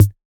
Index of /musicradar/retro-drum-machine-samples/Drums Hits/Tape Path B
RDM_TapeB_MT40-Kick02.wav